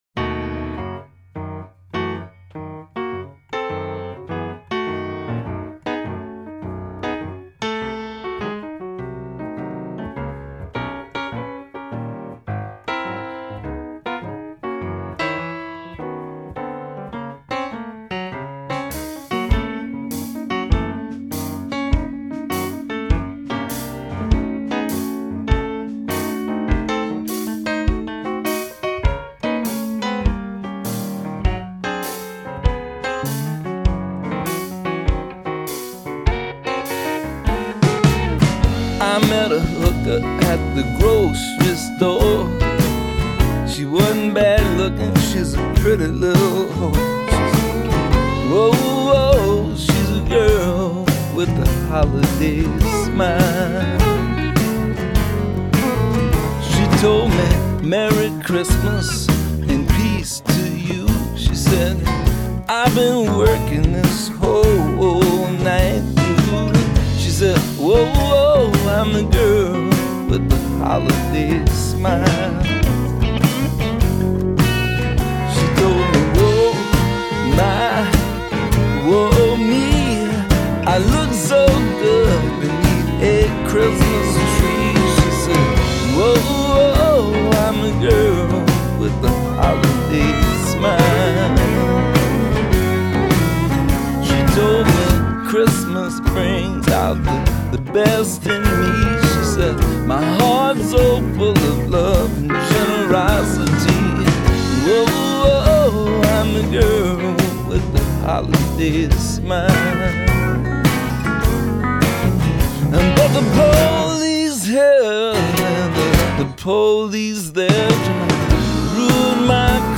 and while it’s dirty fun